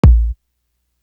Lose Ya Life Kick.wav